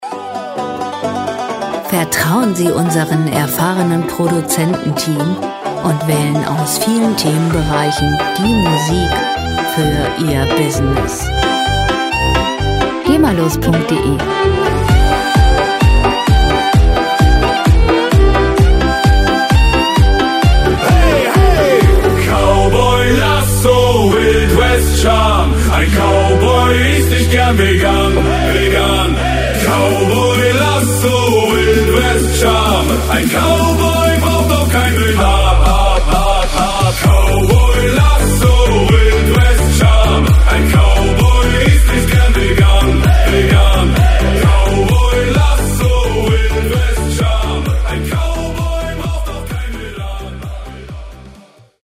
Gema-freie Schlager Pop Musik
Musikstil: EDM Partyschlager
Tempo: 130 bpm
Tonart: G-Dur
Charakter: witzig, urig